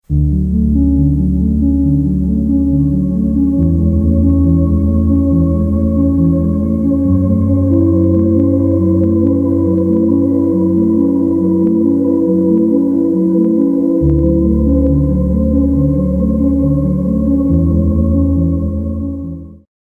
Lamb Chop ASMR Sounds Anyone? Sound Effects Free Download